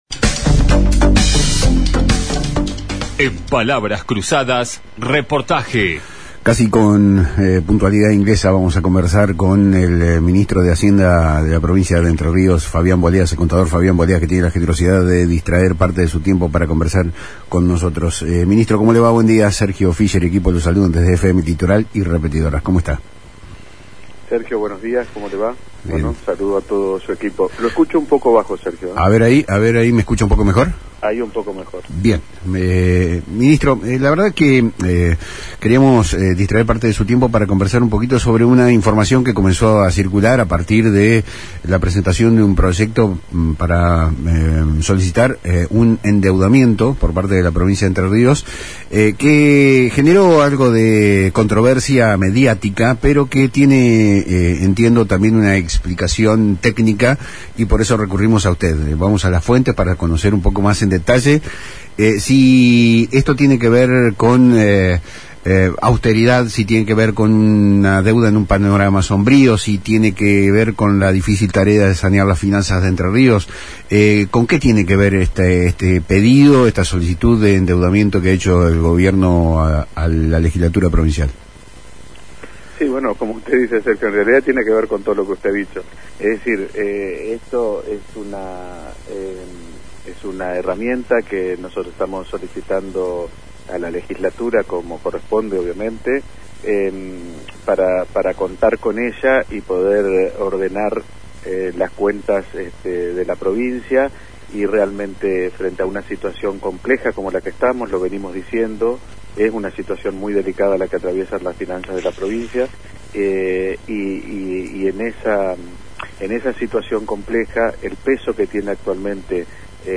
En una entrevista exclusiva brindada a Palabras Cruzadas por FM Litoral de Paraná, el funcionario detalló el plan del gobierno para refinanciar la deuda, aliviar la carga financiera y liberar recursos para la gestión.